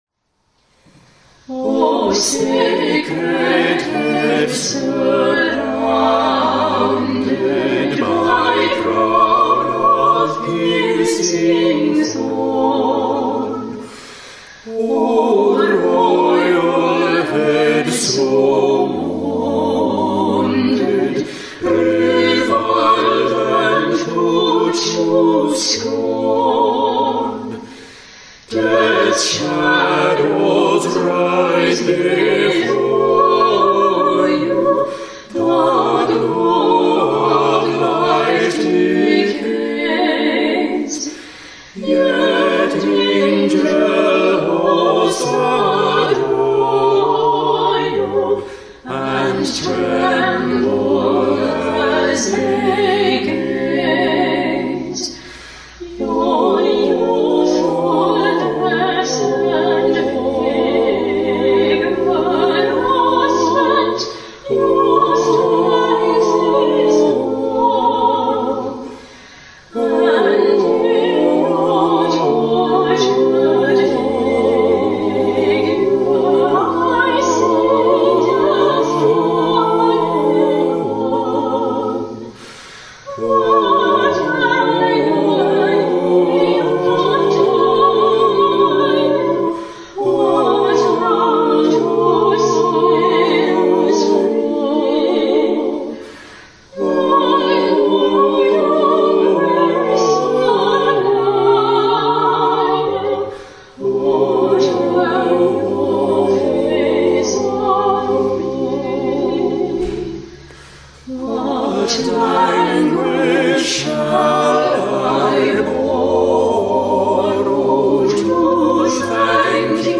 St. James Church Service for April 26th